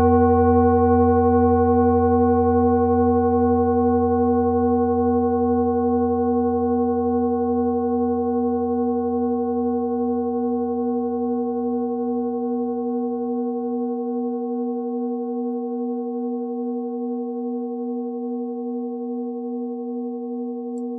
Nepal Klangschale Nr.50 Planetentonschale: Jahreston (Plato)
Klangschale Nepal Nr.50
(Ermittelt mit dem Filzklöppel)
In unserer Tonleiter ist das das "F".
klangschale-nepal-50.wav